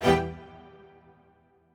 admin-leaf-alice-in-misanthrope/strings34_1_000.ogg at main